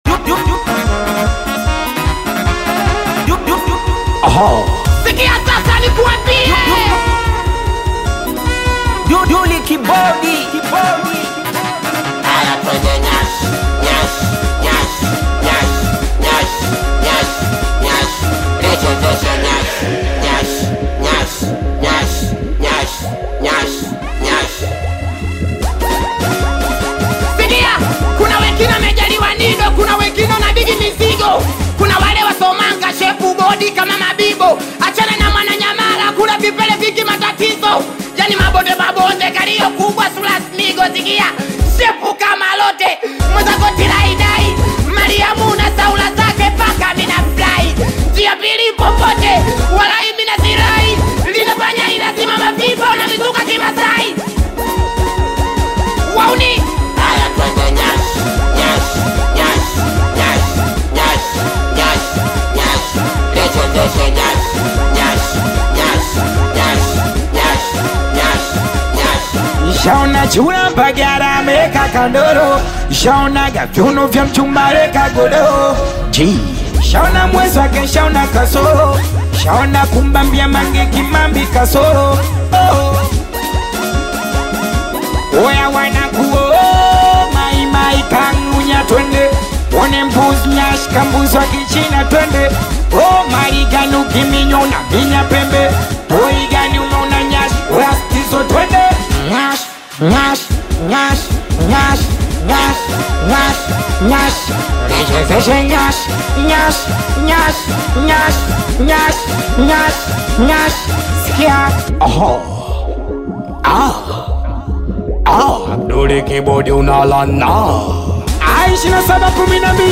AudioSingeli
is a high-energy Singeli single